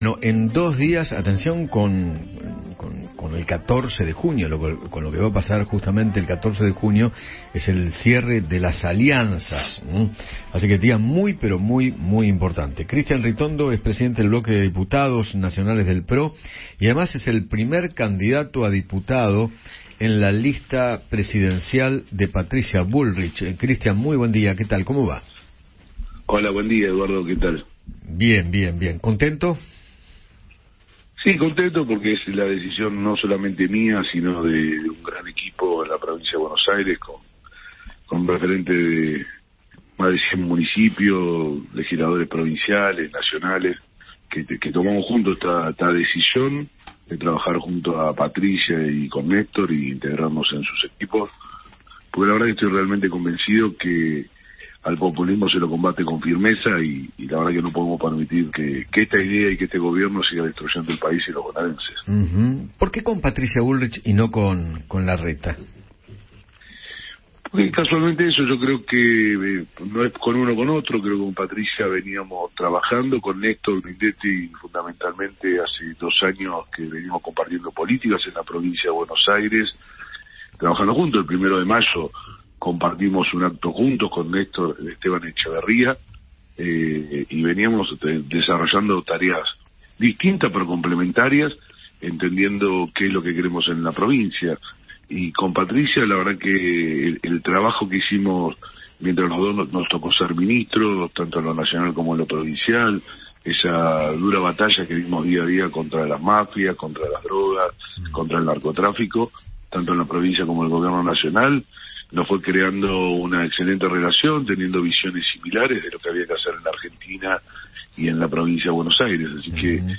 Cristian Ritondo, Presidente del bloque de diputados del PRO, dialogó con Eduardo Feinmann sobre la alianza con Patricia Bullrich y se refirió a la interna de la coalición.